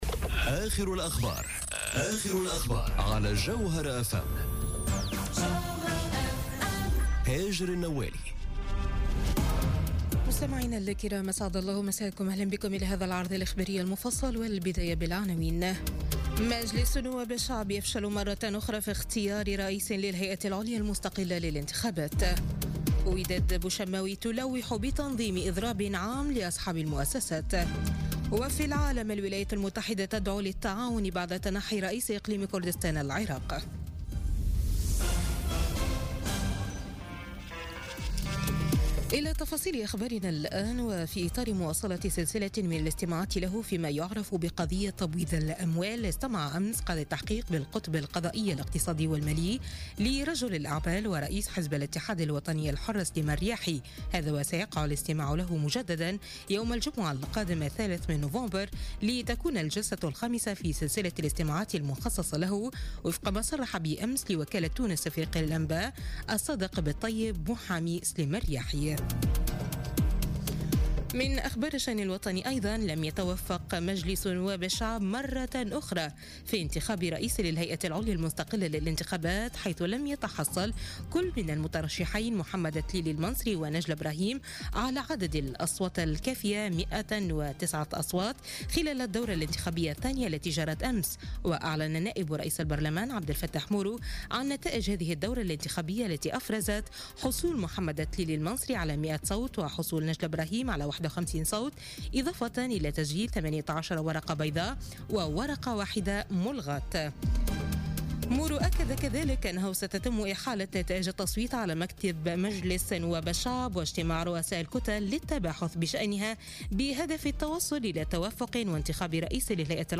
نشرة أخبار منتصف الليل ليوم الثلاثاء 31 أكتوبر 2017